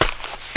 SNEAK DIRT 3.WAV